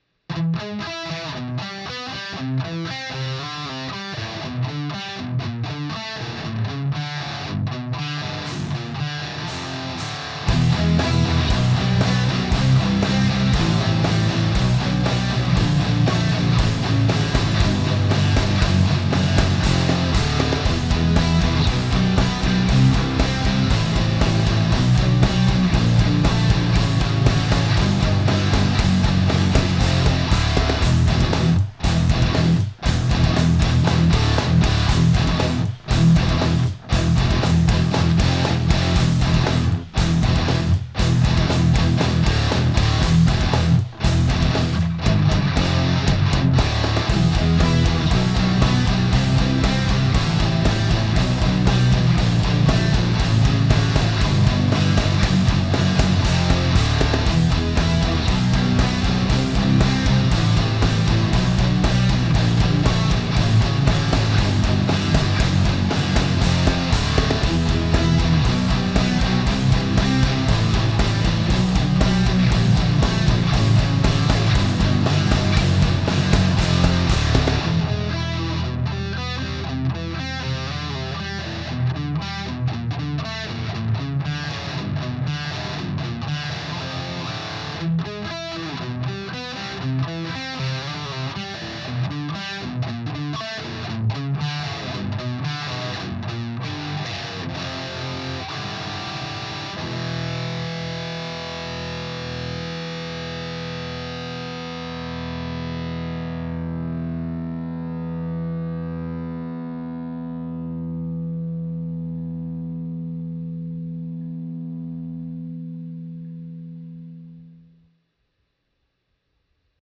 Fuckery w/ Drums
Middle and then two guitars doing each of the other parts. Fender bass down the middle again.
Sorta OK, but a little crispy for my tastes.